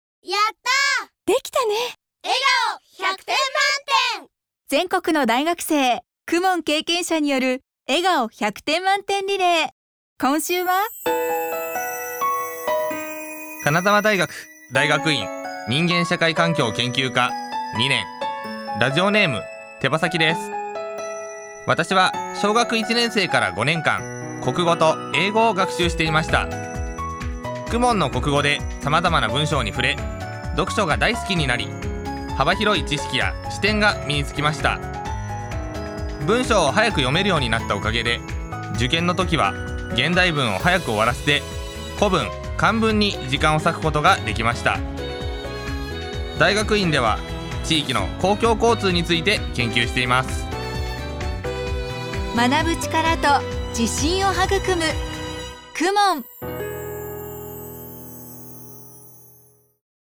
全国の大学生の声